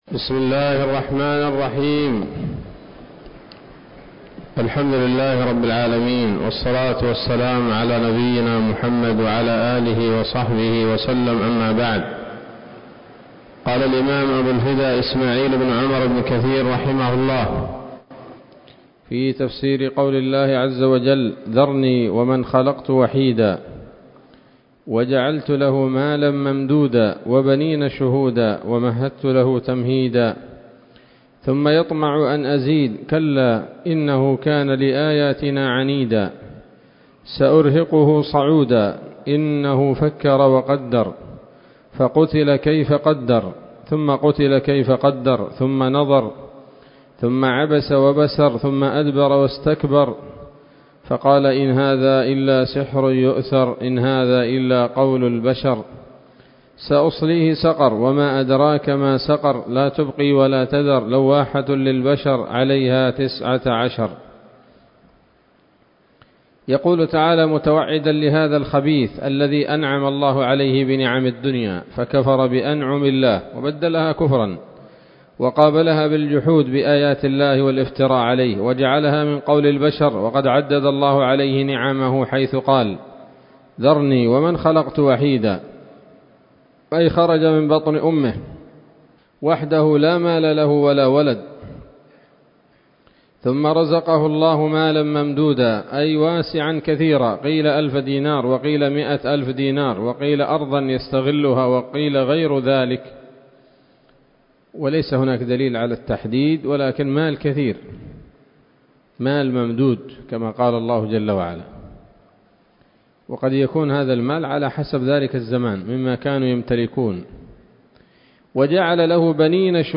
الدرس الثاني من سورة المدثر من تفسير ابن كثير رحمه الله تعالى